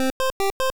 snd_walk.wav